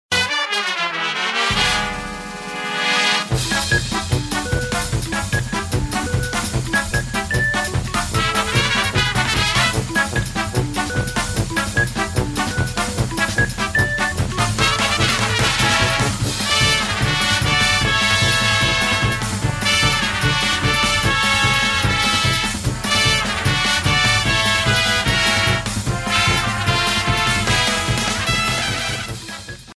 Fade-out added